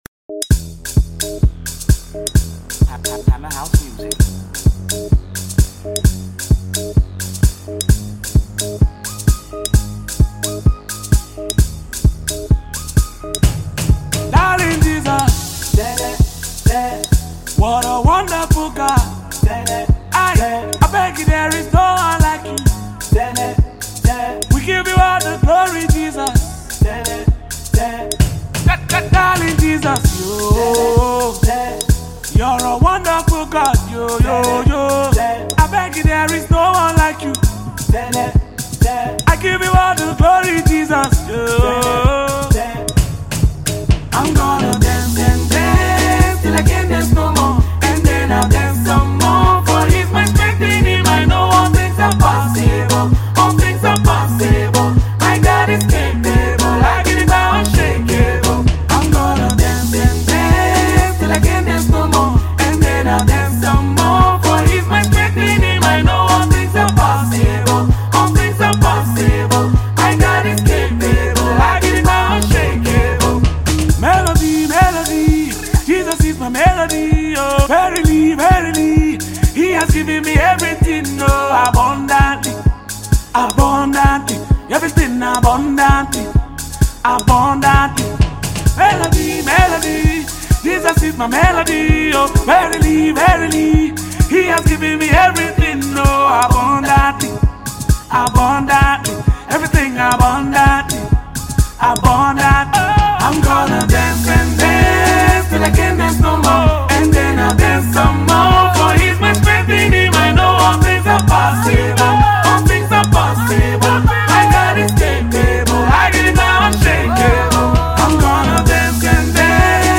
Music
enthralling and addictive praise song